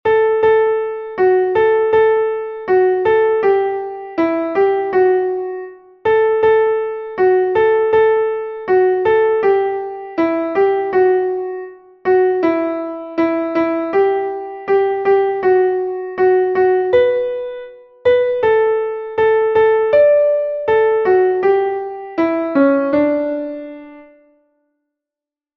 ist ein klassisches Weihnachtslied